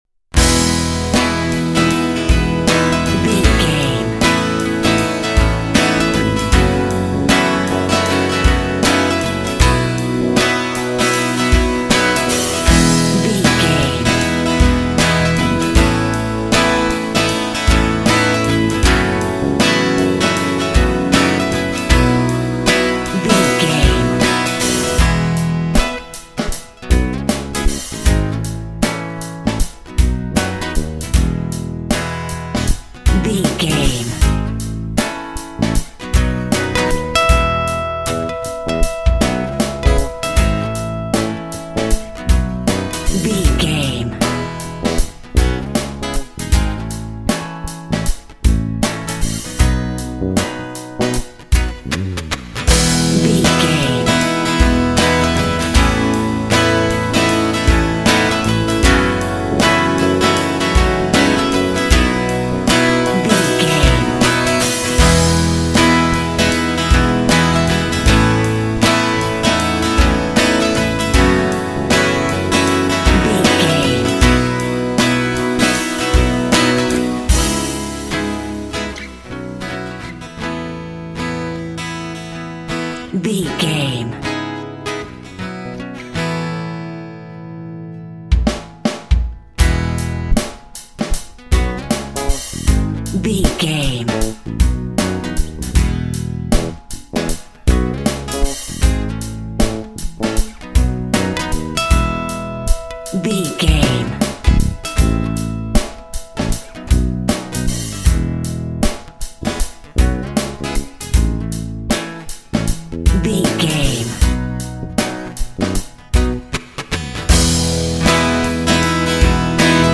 Ionian/Major
pop
cheesy
pop rock
drums
bass guitar
electric guitar
piano
hammond organ